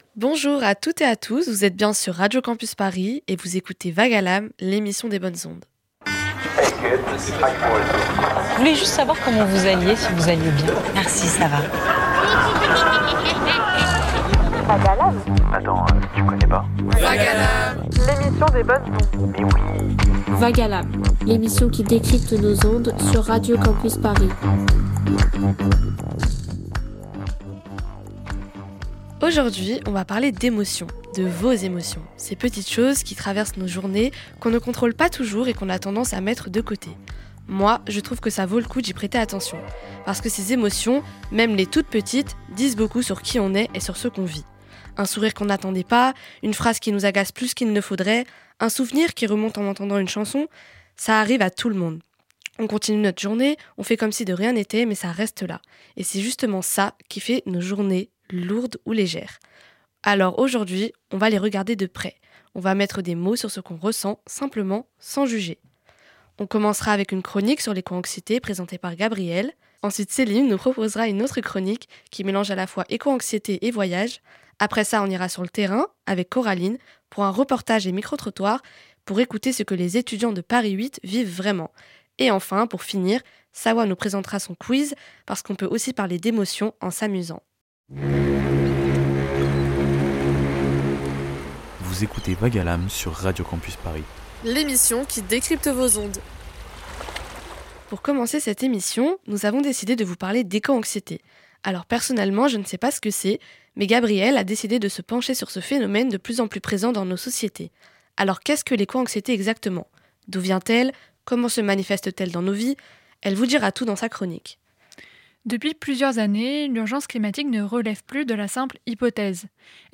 Dans un monde traversé par des crises politiques et climatiques quelles places occupent encore nos émotions ? C’est à cette question que Vague à l’âme, l’émission des étudiant·es de Paris 8, a tenté de répondre.